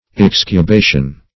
Search Result for " excubation" : The Collaborative International Dictionary of English v.0.48: Excubation \Ex`cu*ba"tion\n. [L. excubatio, fr. excubare to lie out on guard; ex out on guard; ex out + cubare to lie down.] A keeping watch.